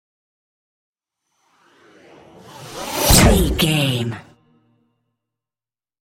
Pass by fast speed engine
Sound Effects
pass by
car
vehicle